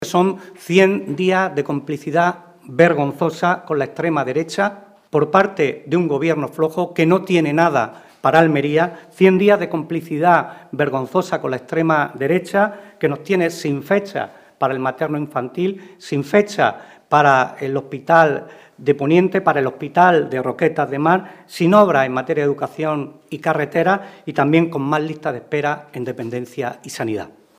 Rueda de prensa de los parlamentarios andaluces